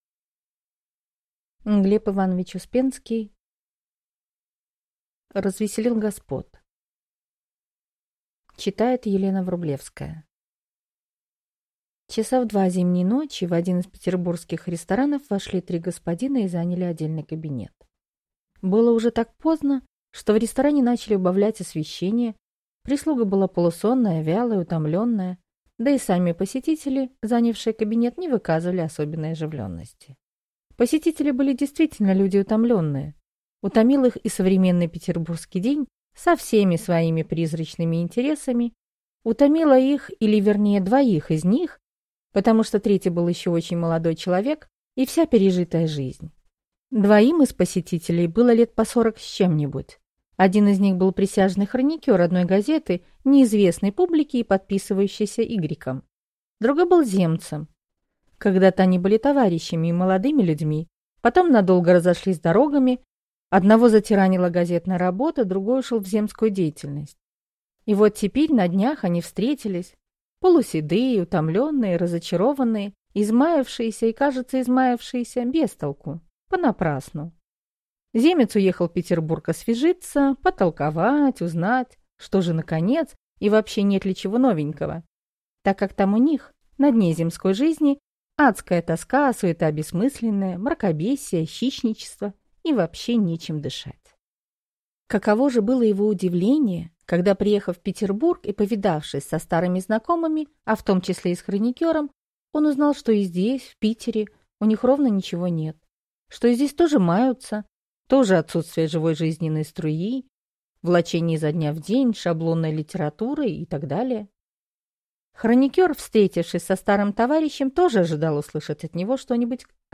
Аудиокнига Развеселил господ | Библиотека аудиокниг